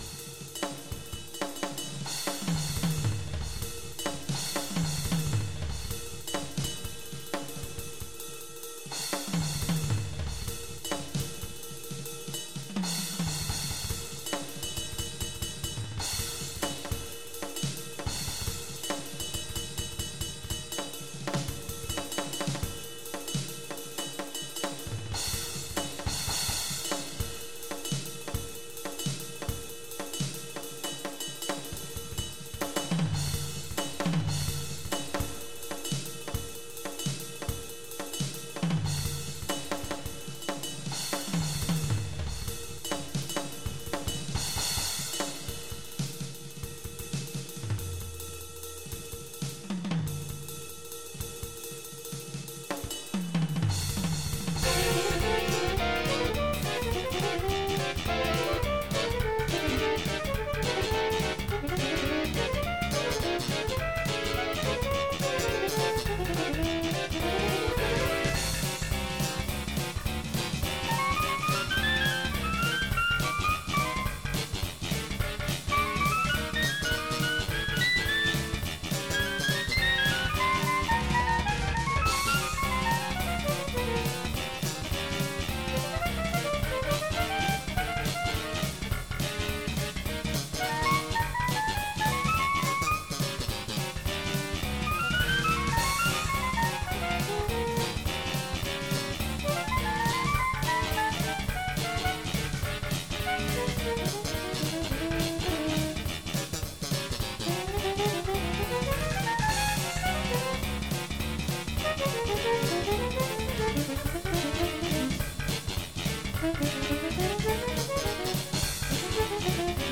I quite like this version but I wanted to do something more noisy.